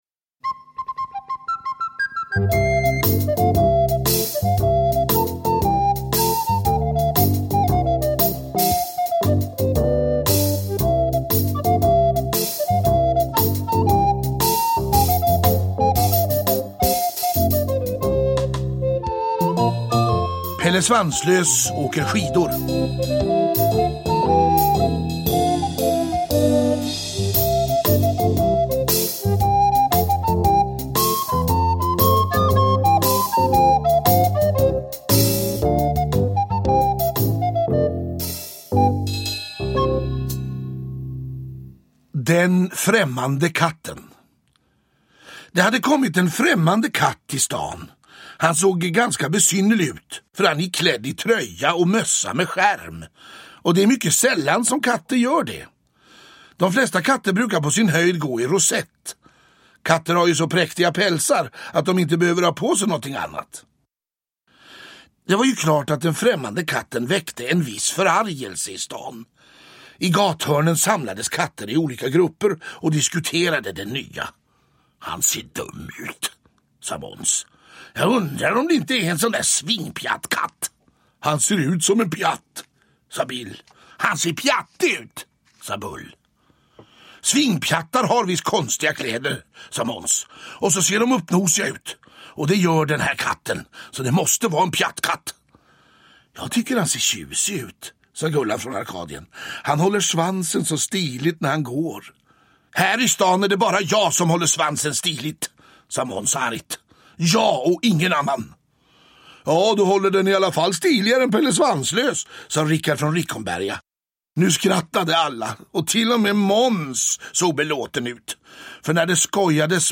Pelle Svanslös åker skidor – Ljudbok – Laddas ner
Peter Harryson läser med stor inlevelse Gösta Knutssons klassiska berättelser om Pelle Svanslös, Maja Gräddnos, elake Måns, Bill och Bull och alla de övriga katterna på Åsgränd i Uppsala.
Uppläsare: Peter Harryson